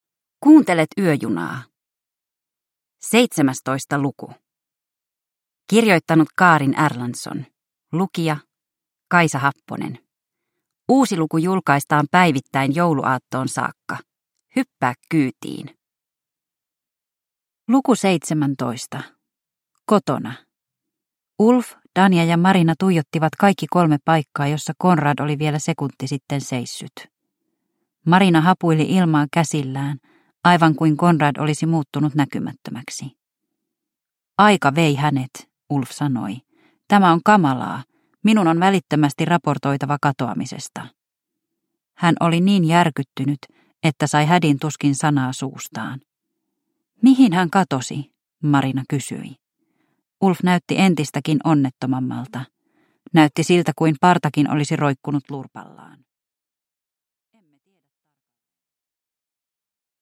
Yöjuna luku 17 – Ljudbok